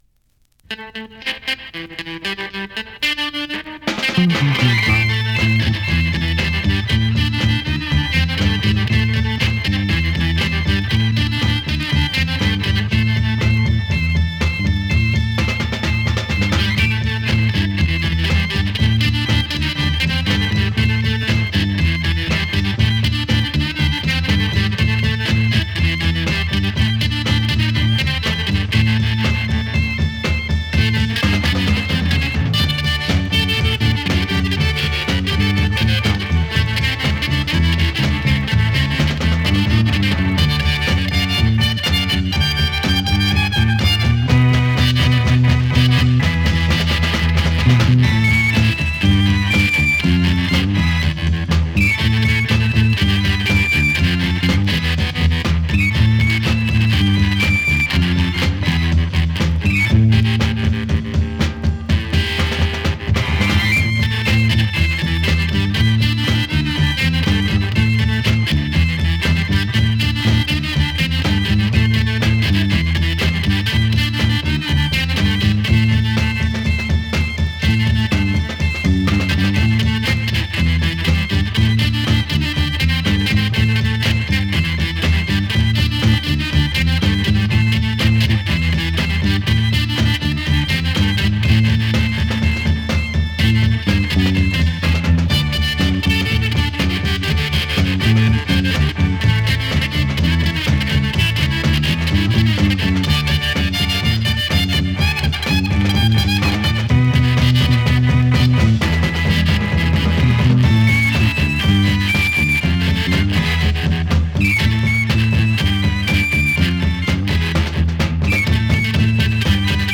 Rare psych fuzz garage double sider !!
試聴 (実際の出品物からの録音です)